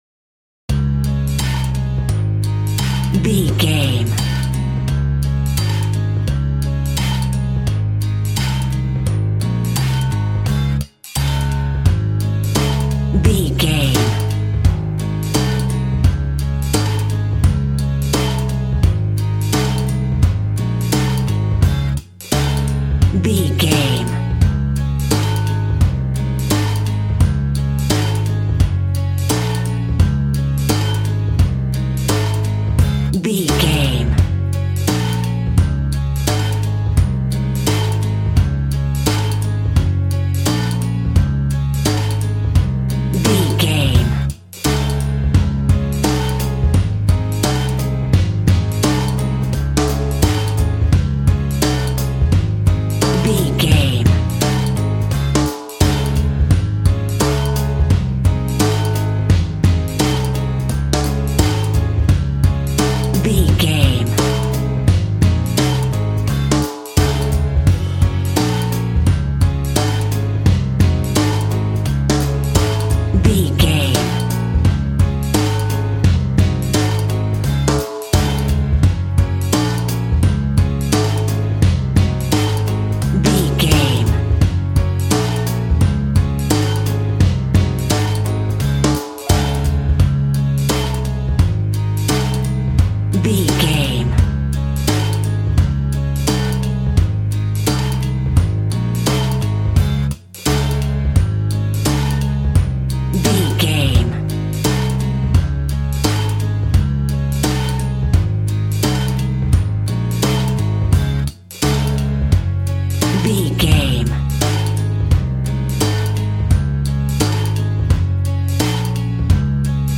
Ionian/Major
D♭
childrens music
instrumentals
fun
childlike
cute
happy
kids piano